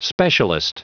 Prononciation du mot specialist en anglais (fichier audio)
Prononciation du mot : specialist